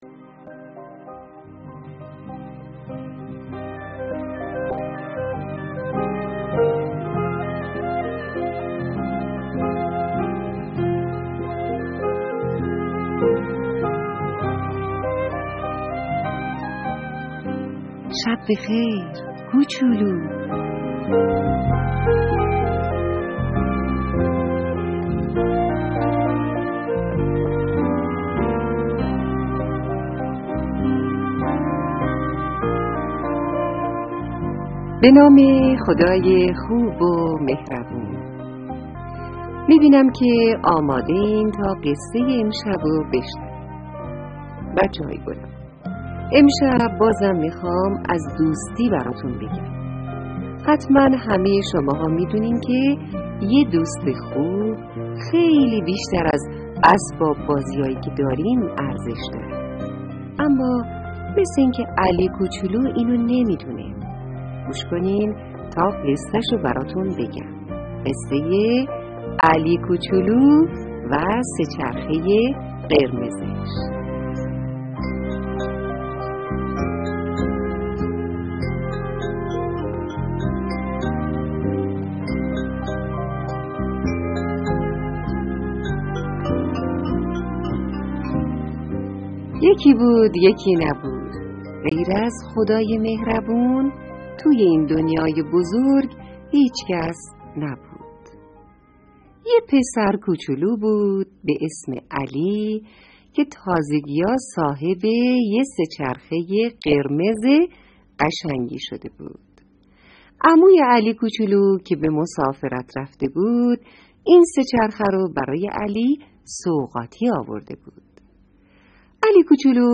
قصه کودکانه صوتی شب + داستان کوتاه کودکانه برای پیش دبستانی و دبستانی ها